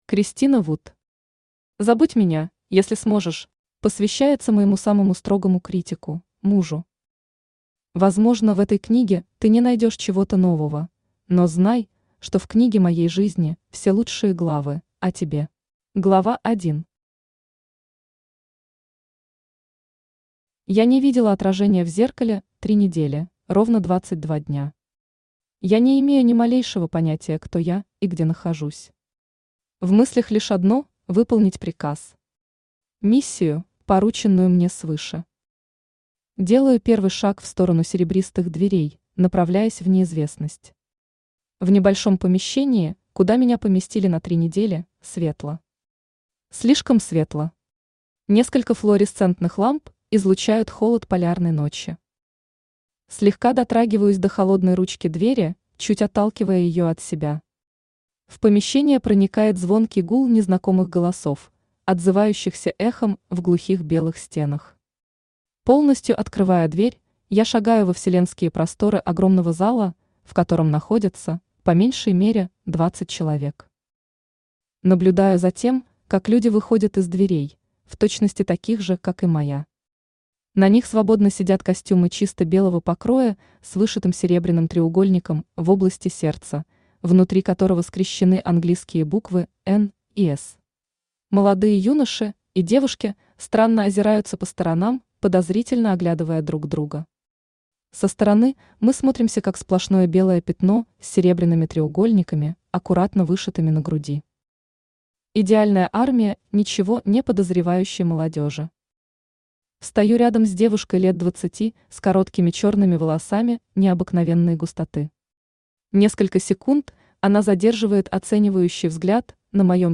Aудиокнига Забудь меня, если сможешь Автор Кристина Вуд Читает аудиокнигу Авточтец ЛитРес.